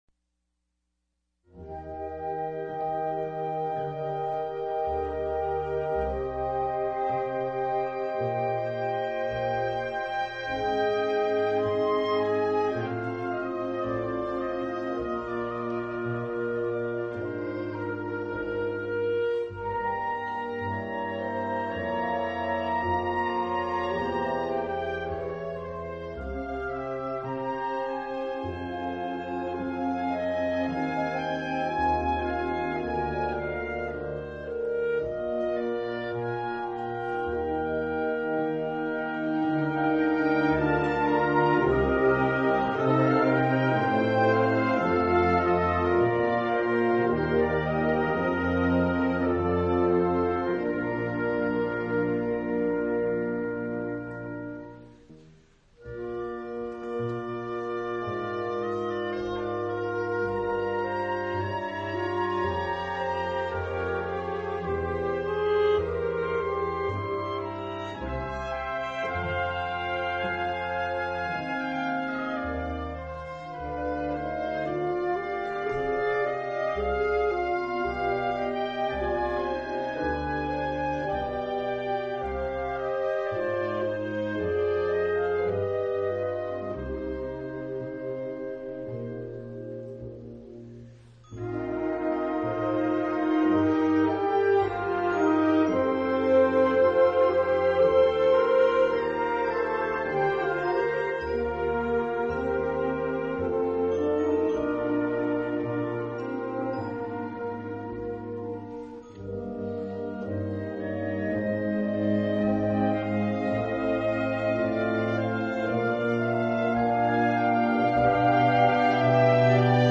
Key: E-flat Major (original key: D Major)
This arrangement is written for Wind Ensemble.
Repeats are omitted